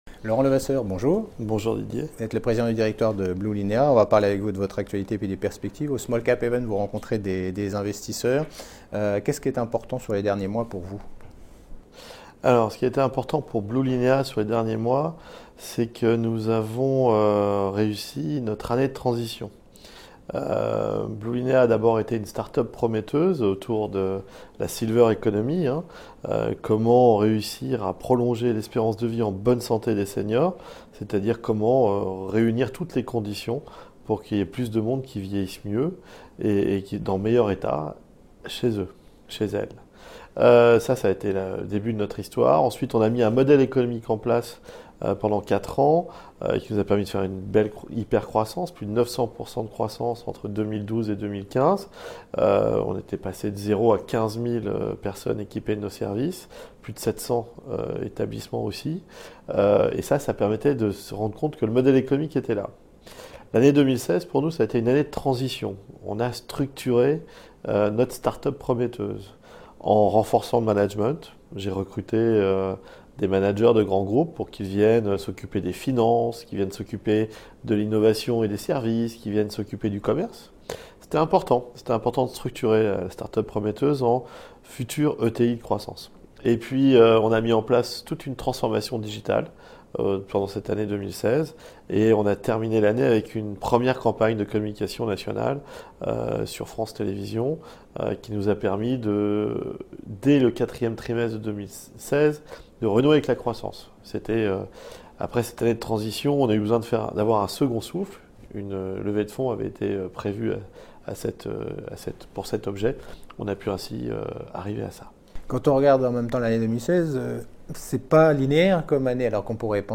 La Web Tv partenaire du SmallCap Event 2017 organisé par CF&B Communication.